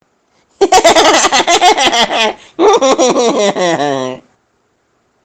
Play, download and share risadinha escrota original sound button!!!!